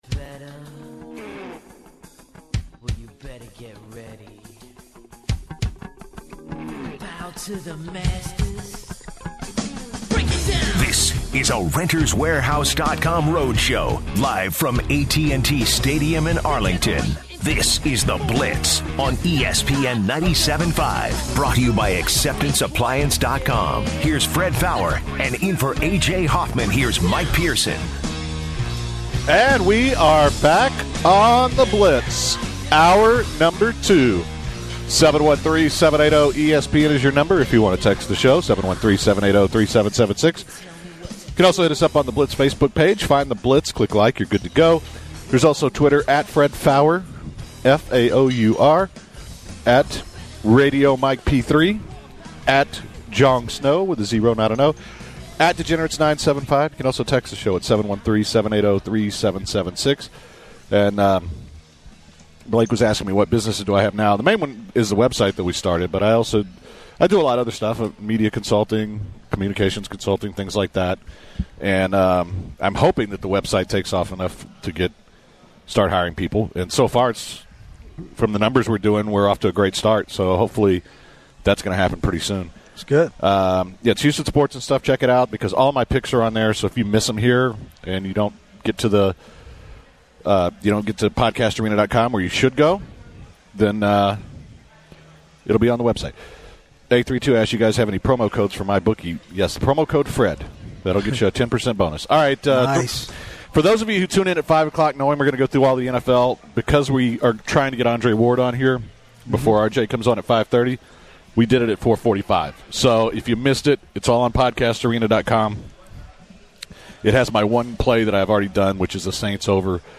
the guys are recording the show from Dallas, Texas. The guys give their College Football and NFL predictions.